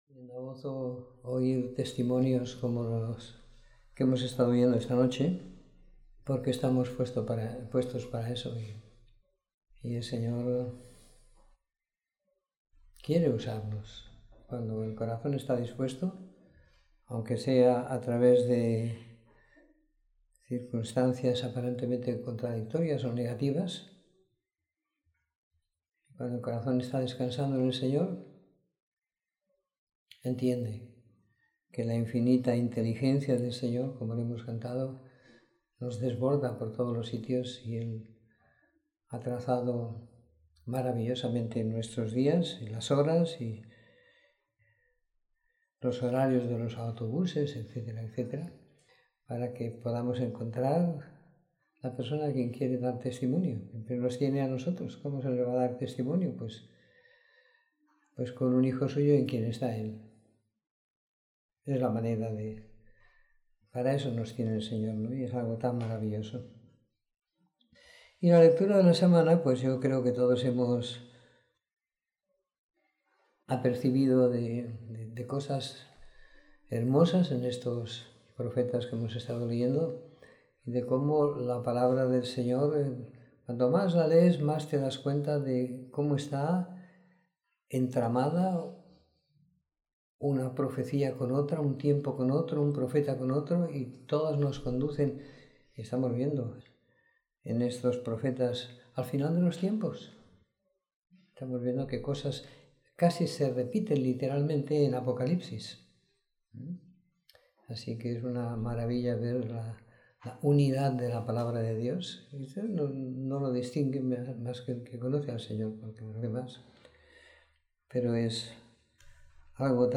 Reunión del Viernes